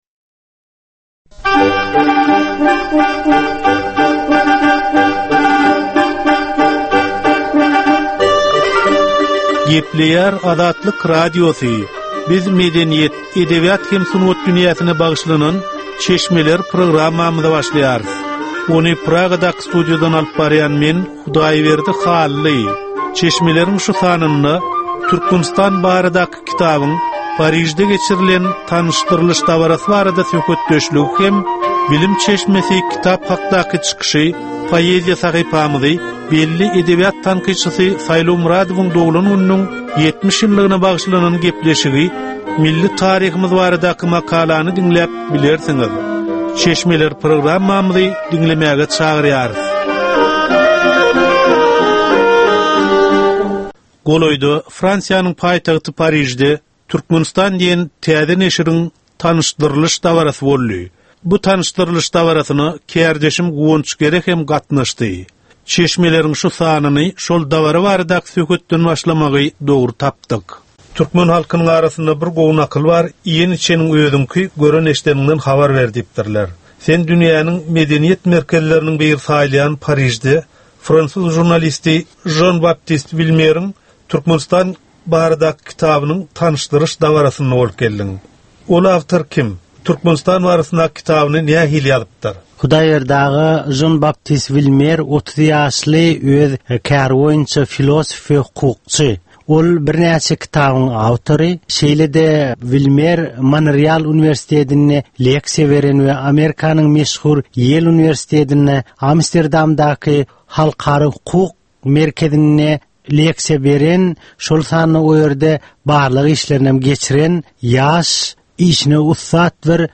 Edebi, medeni we taryhy temalardan 25 minutlyk ýörite gepleşik.